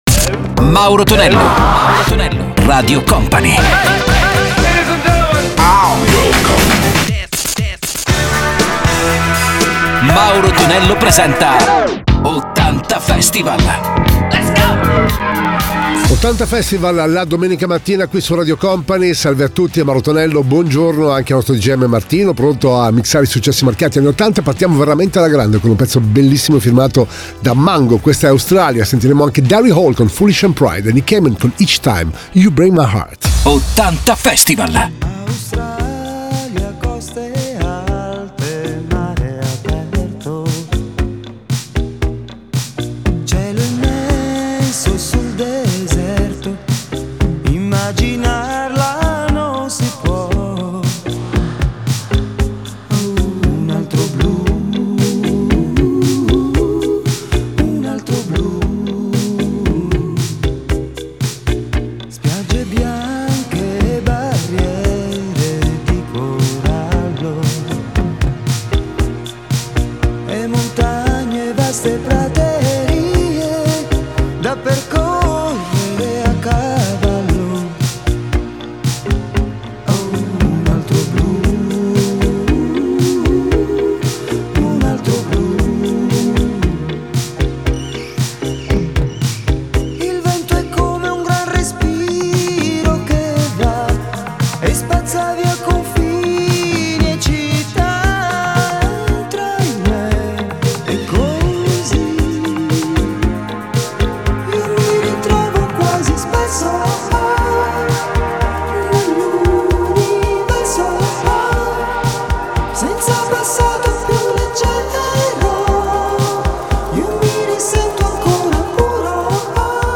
i maggiori successi degli anni 70 e 80